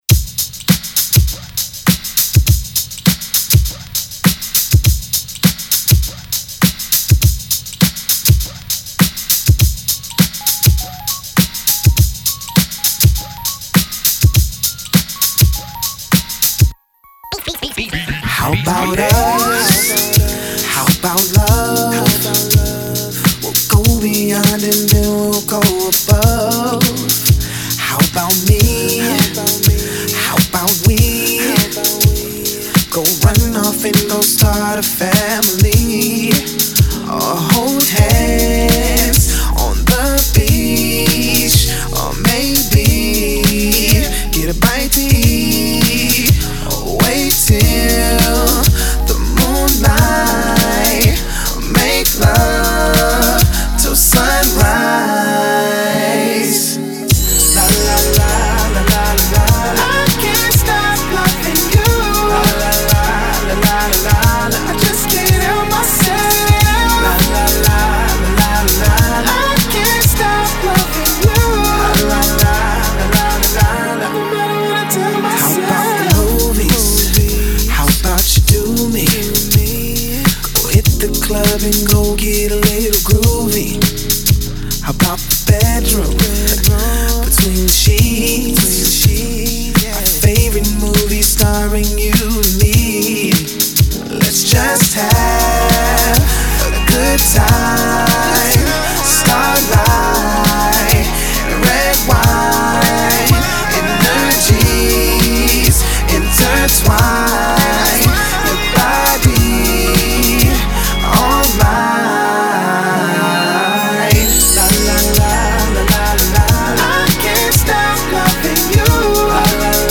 Singer · Songwriter · Dancer